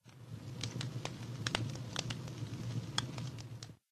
Minecraft Version Minecraft Version latest Latest Release | Latest Snapshot latest / assets / minecraft / sounds / block / campfire / crackle3.ogg Compare With Compare With Latest Release | Latest Snapshot
crackle3.ogg